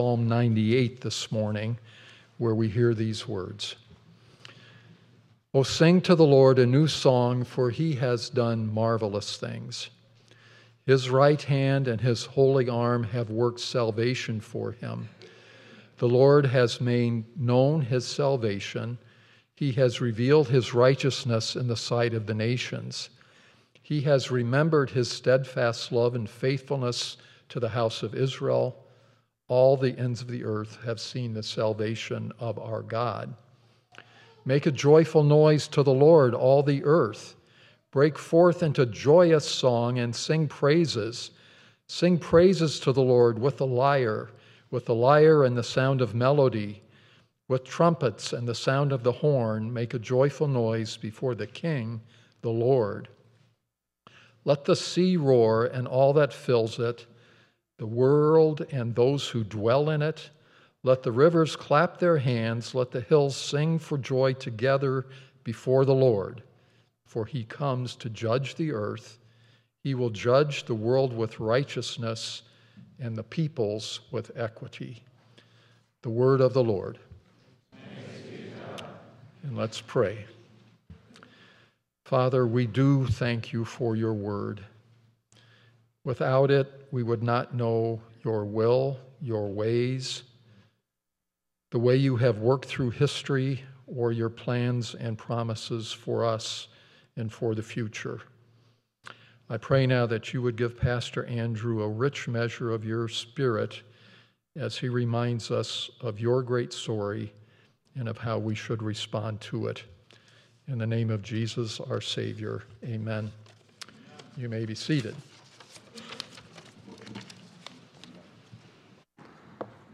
8.10.25 sermon.m4a